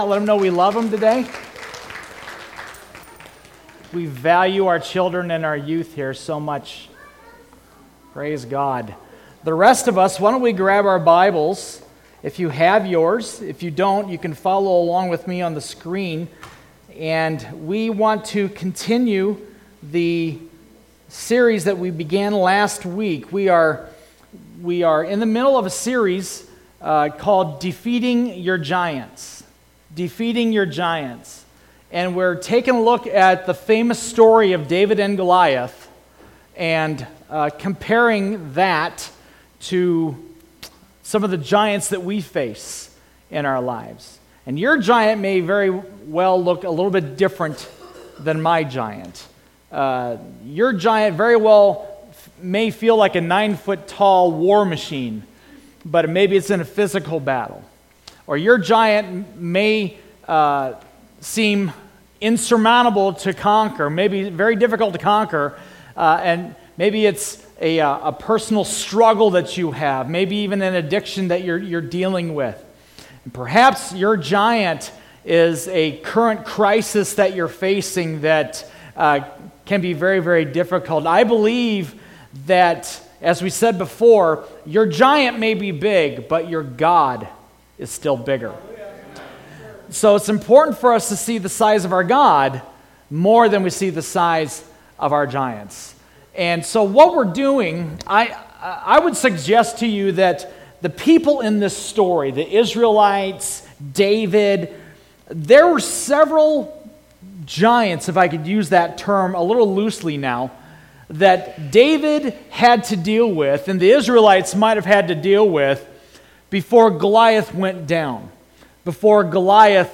Sermons - Bethel Church of Tallmadge